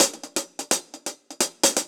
Index of /musicradar/ultimate-hihat-samples/128bpm
UHH_AcoustiHatB_128-04.wav